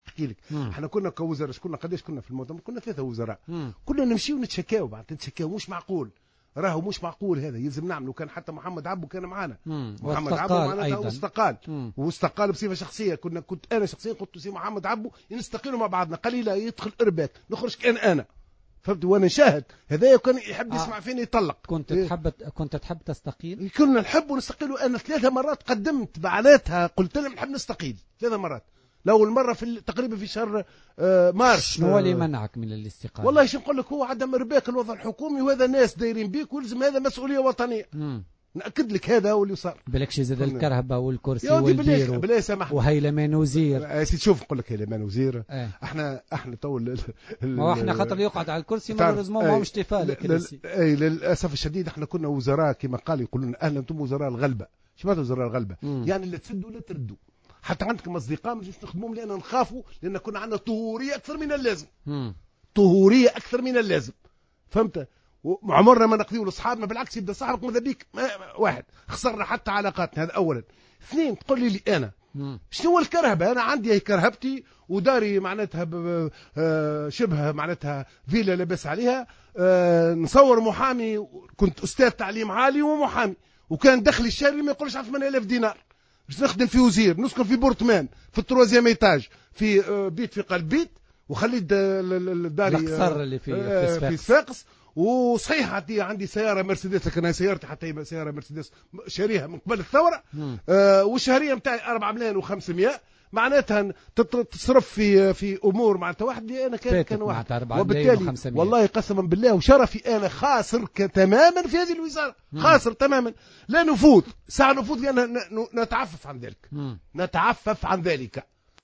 وأعلن المعطر في مقابلة صحفية مع "الجوهرة أف أم" في برنامج "بوليتيكا" أنه قدم استقالته ثلاث مرات إلا أنه تراجع عنها لعدم ارباك العمل الحكومي ومن باب المسؤولية الوطنية، بحسب تصريحه.